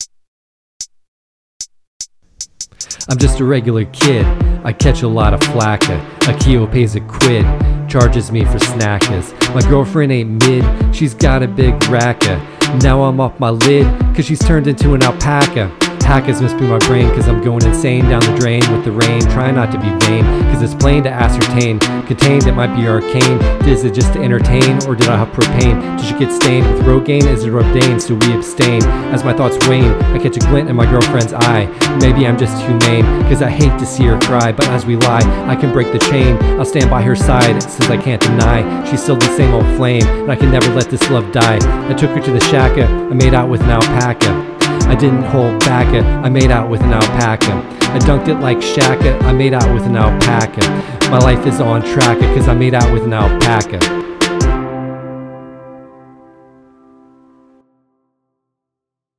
ep90-Paca-Plus-Rap.mp3